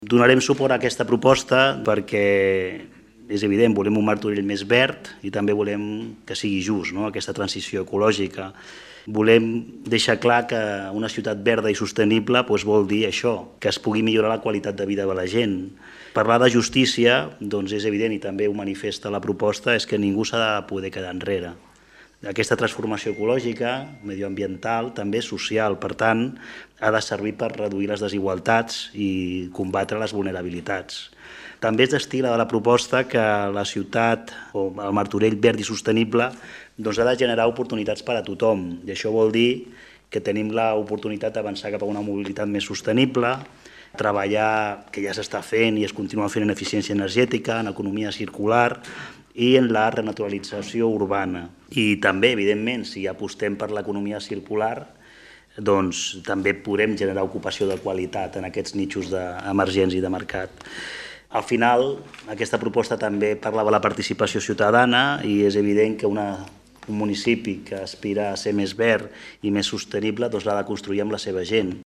Javier González, regidor de Transició Digital i Sostenible